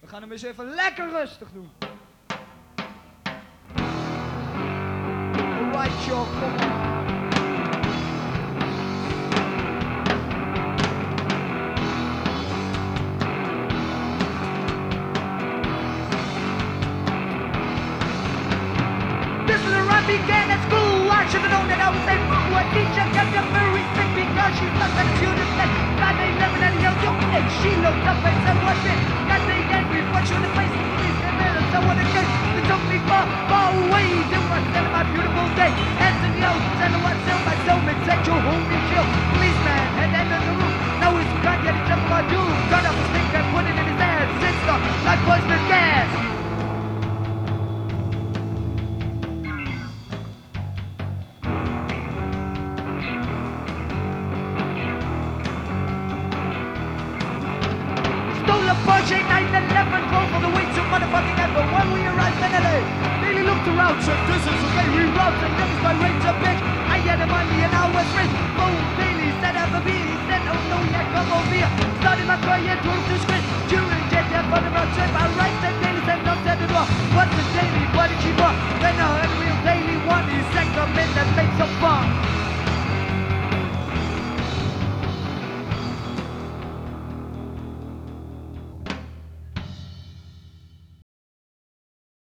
Live in Atak Enschede